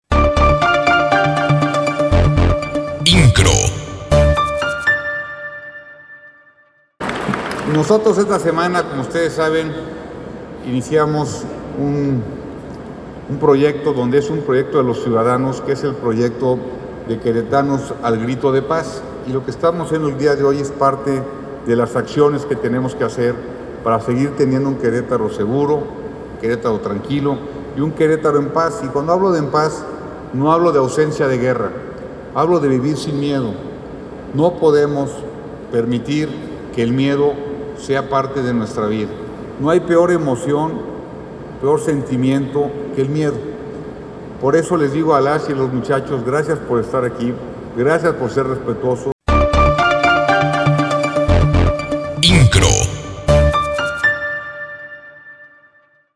Mauricio Kuri González, gobernador del estado, encabezó la puesta en marcha del programa “Contigo Aprendemos” en el Querétaro Centro de Congresos.